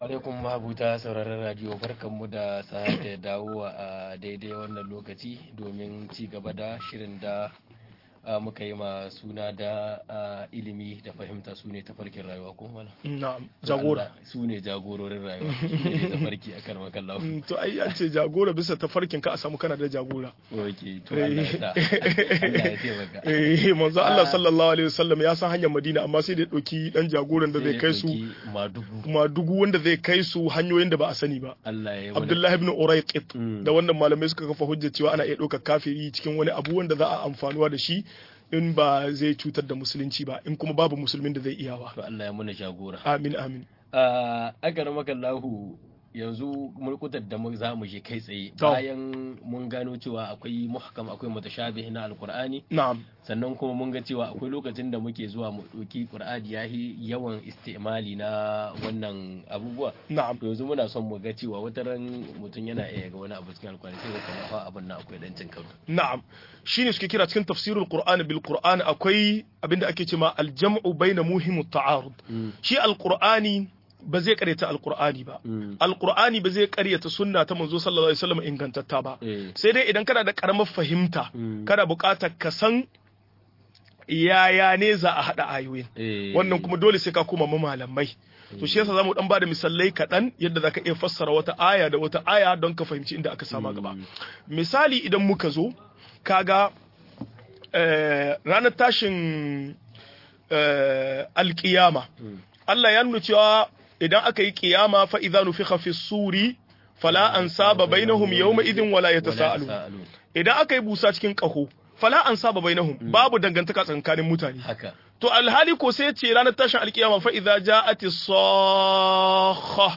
Ka'idodin hardace alkur'ani-5 - MUHADARA